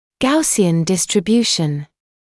[‘gausɪən ˌdɪstrɪ’bjuːʃn][‘гаусиэн ˌдистри’бйуːшн]гауссово распределение, нормальное распределение